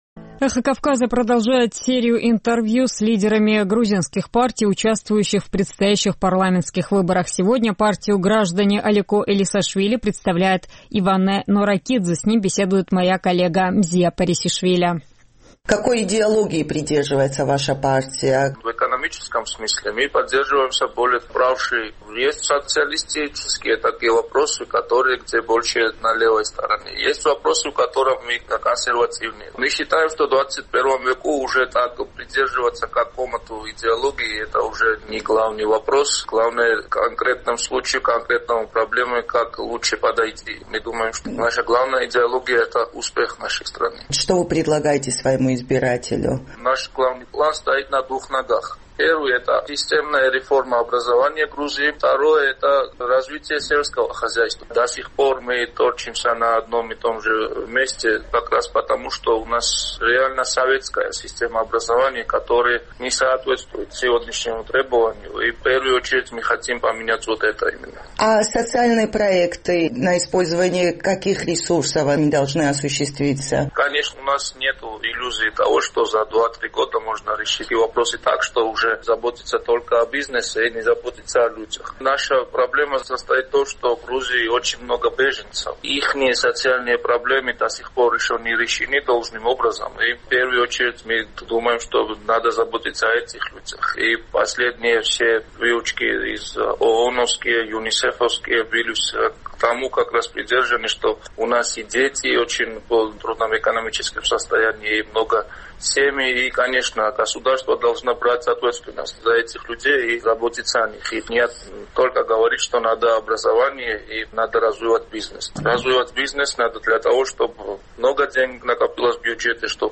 Мы продолжаем серию интервью с лидерами партий, участвующих в предстоящих парламентских выборах.